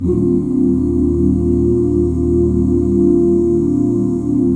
EBMAJ7 OOO.wav